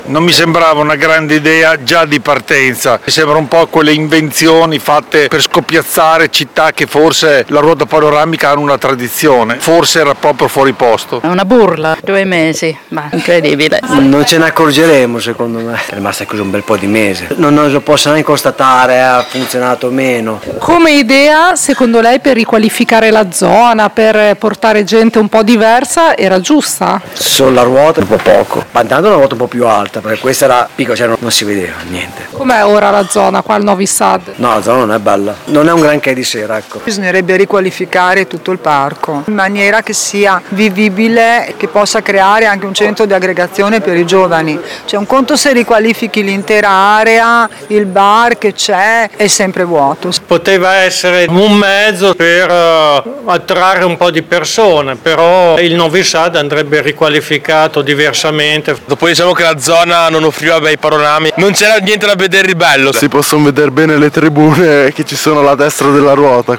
Qui sotto le interviste a chi vive e lavora in zona